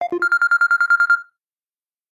• Качество: 130, Stereo
Стандартный рингтон